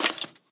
minecraft / sounds / mob / skeleton / step2.ogg
step2.ogg